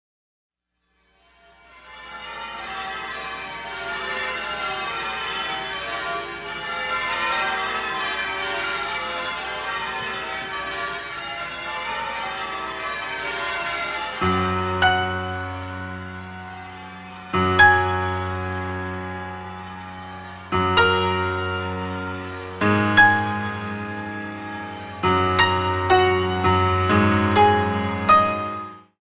The Original PIANO Compositions
good for ballet and modern lyrical